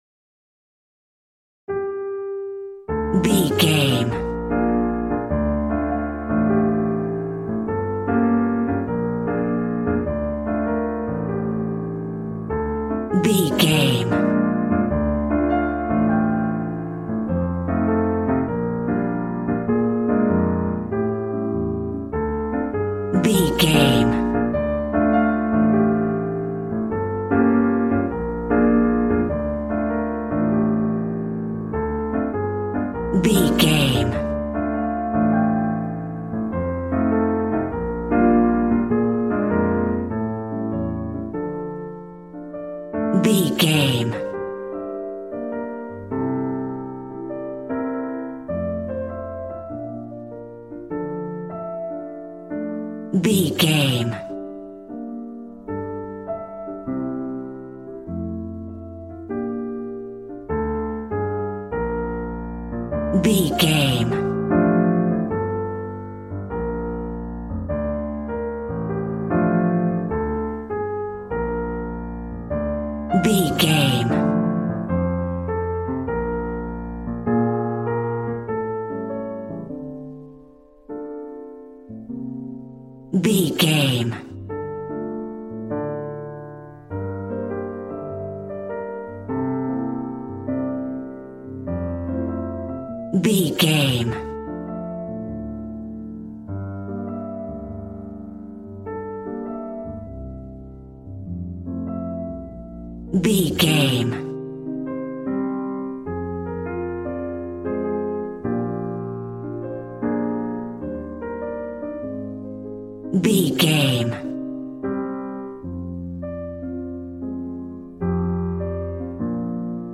Smooth jazz piano mixed with jazz bass and cool jazz drums.,
Aeolian/Minor
sexy
piano